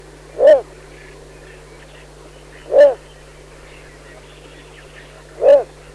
Little Bittern
Little-Bittern.mp3